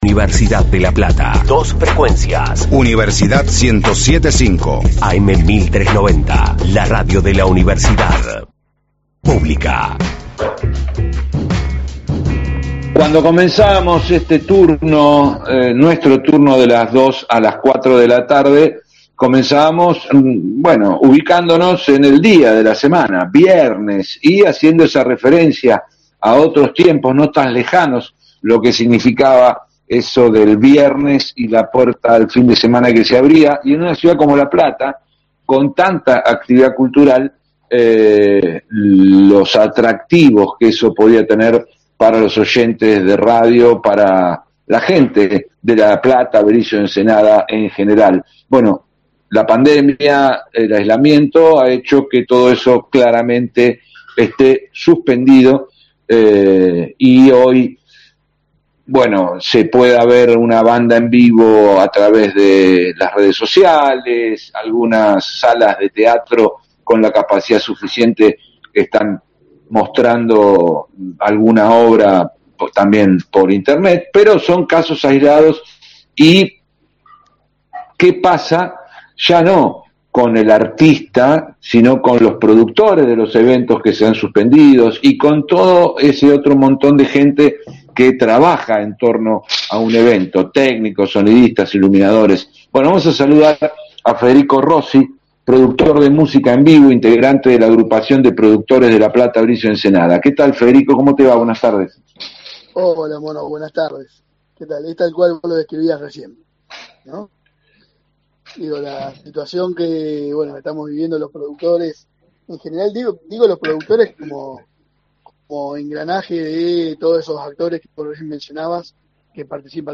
La entrevista completa: